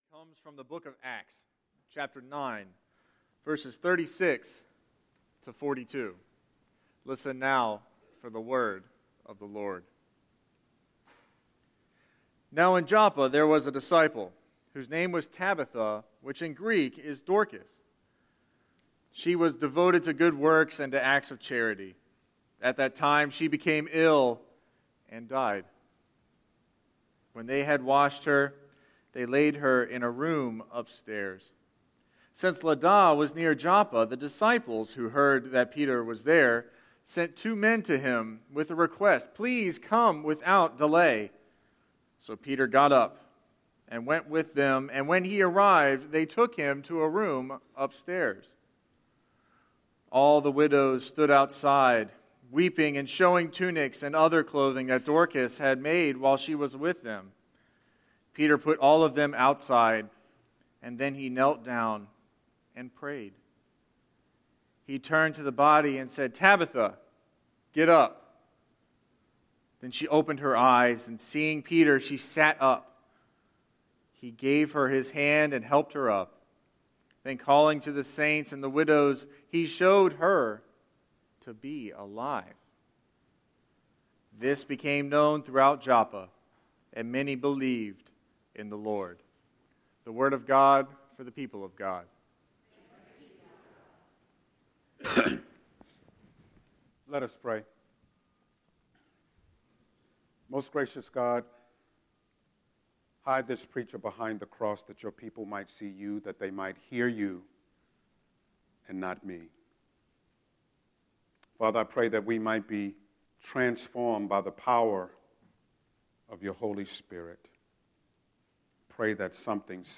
Listen to this week’s Scripture and Sermon
04-17-Scripture-and-Sermon.mp3